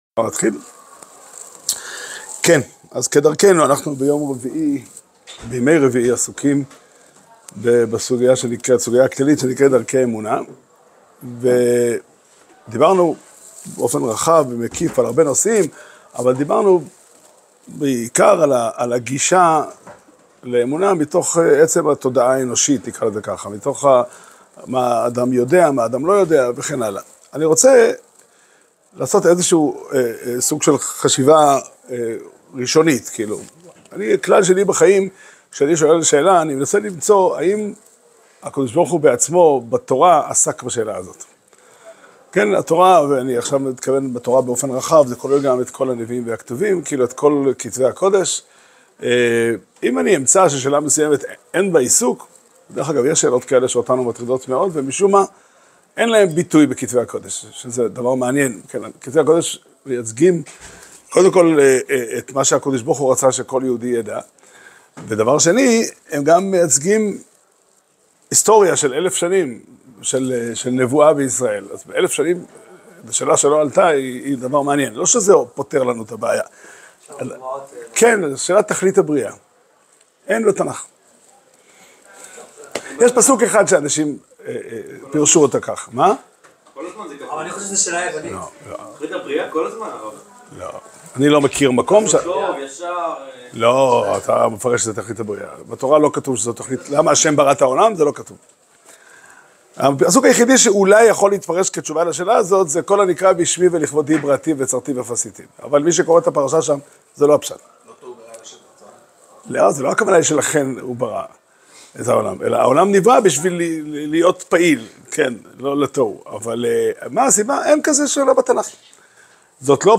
שיעור שנמסר בבית המדרש פתחי עולם בתאריך כ' שבט תשפ"ה